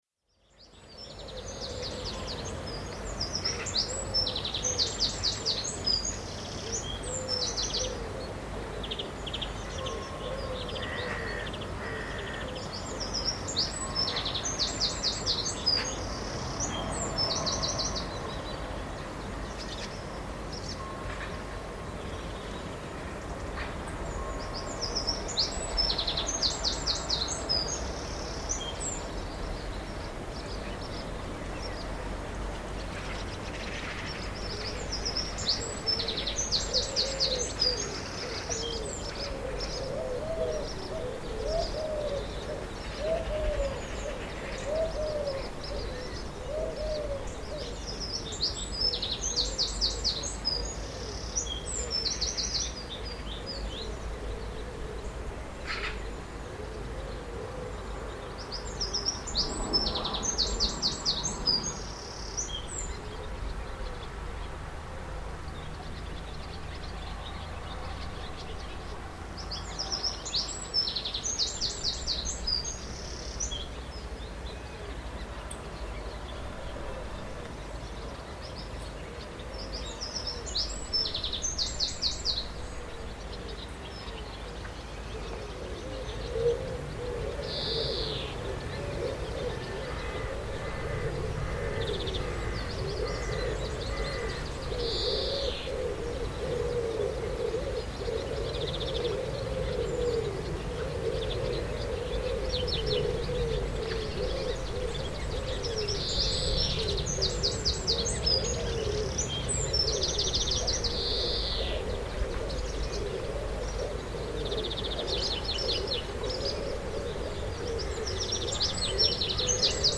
Birdsong audio for Classic FM test transmissions